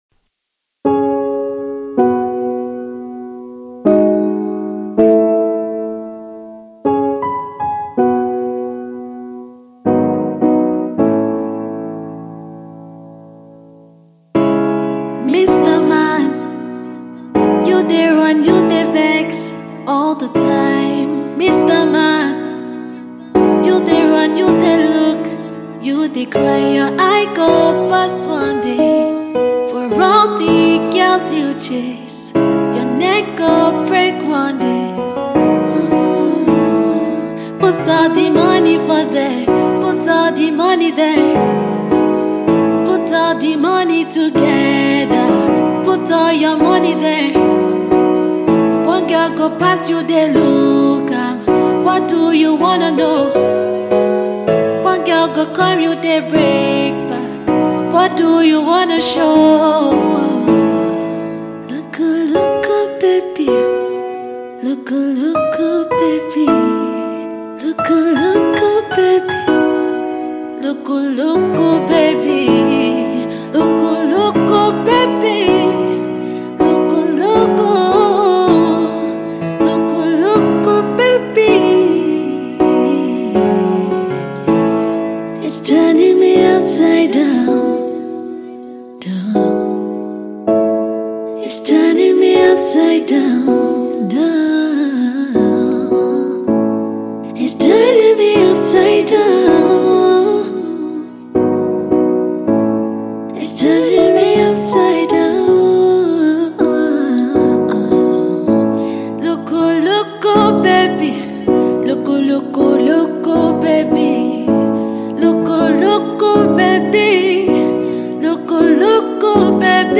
soul & Alternative
Her delivery; soothing and urbane.